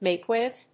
アクセントmáke with…